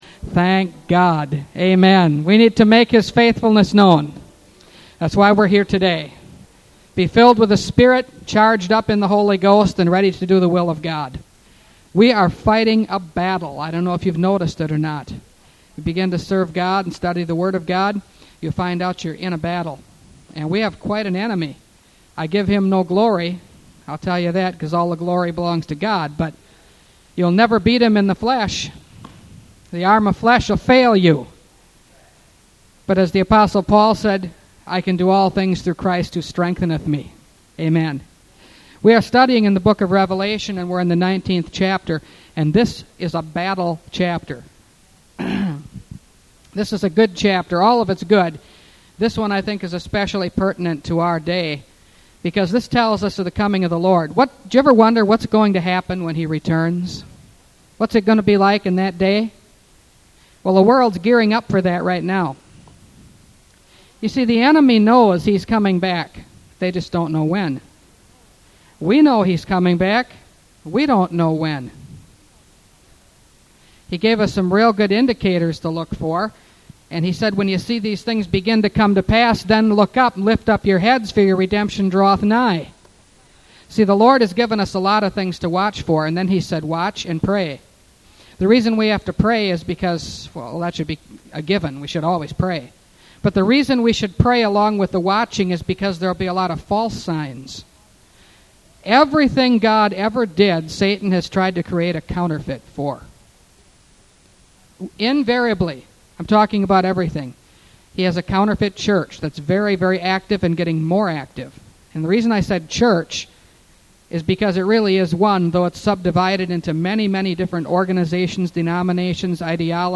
Revelation Series – Part 41 – Last Trumpet Ministries – Truth Tabernacle – Sermon Library